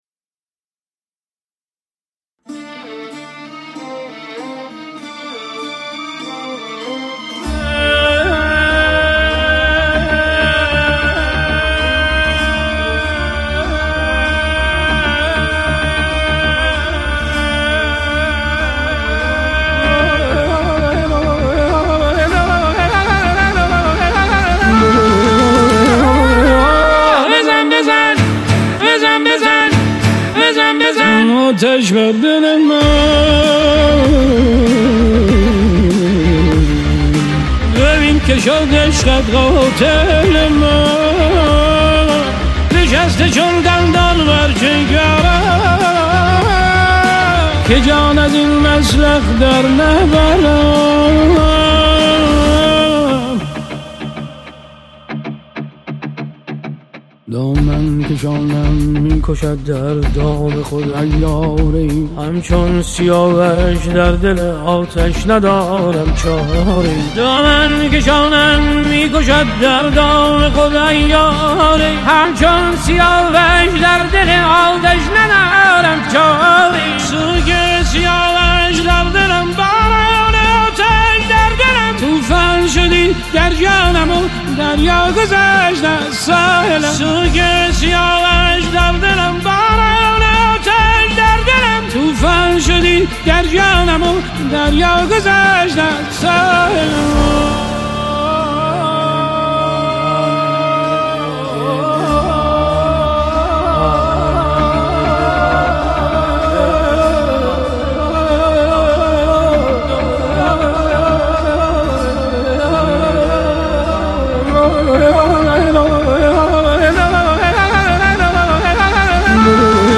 Эрон мусиқаси